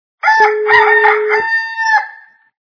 » Звуки » Природа животные » Звук - Петух
При прослушивании Звук - Петух качество понижено и присутствуют гудки.
Звук Звук - Петух